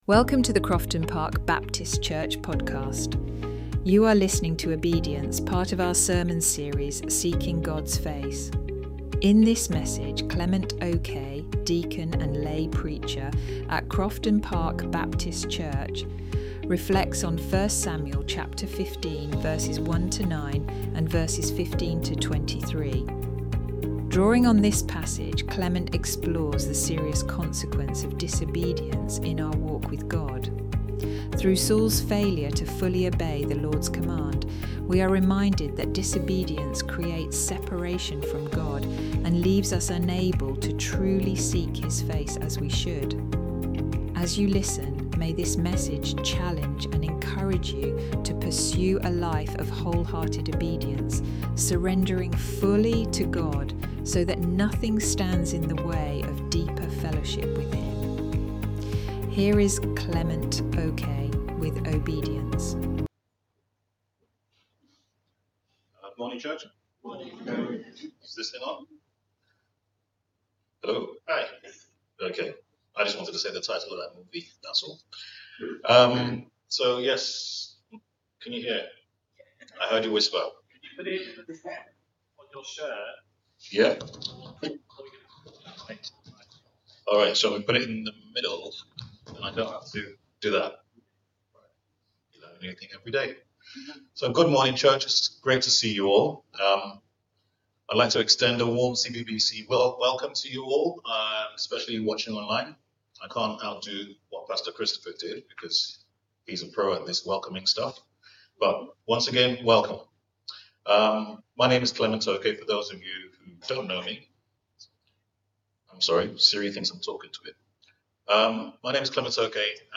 You are listening to Obedience, part of our sermon series, Seeking God’s Face.